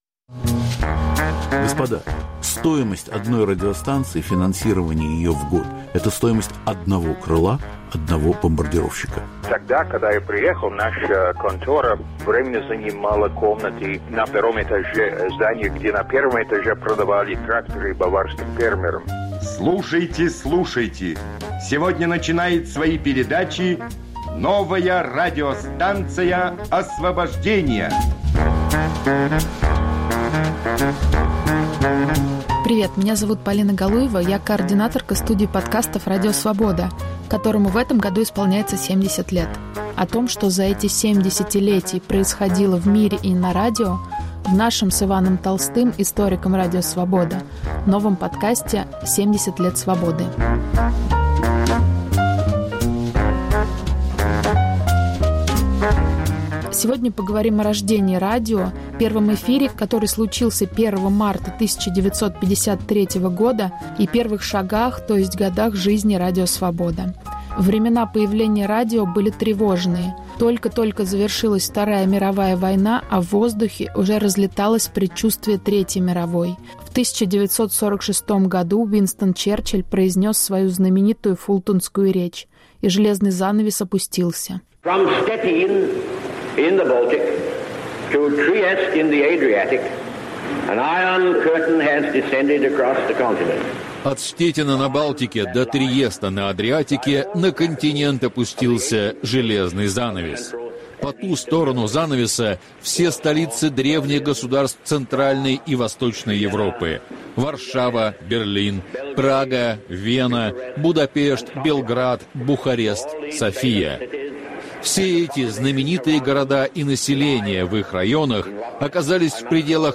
Рождение радио, первый эфир, смерть Сталина и глушилки. Повтор эфира от 24 марта 2023 года.